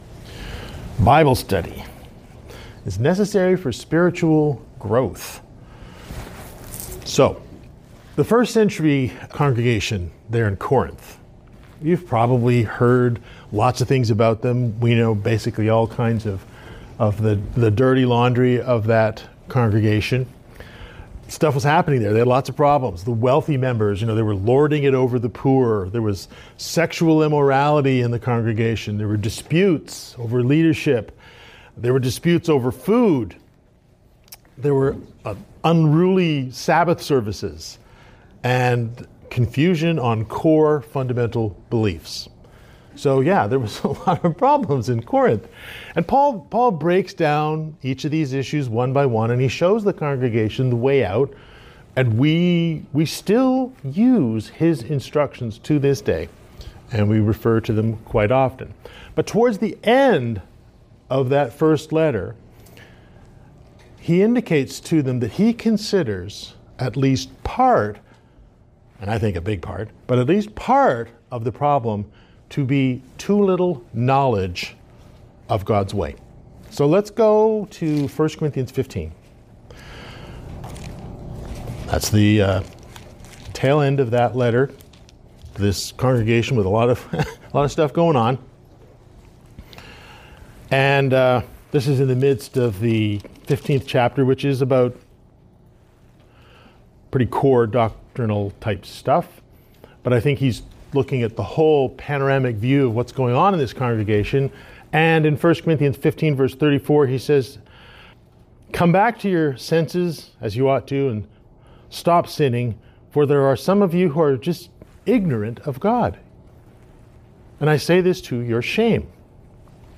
This is part of a series of related sermons that build on each other.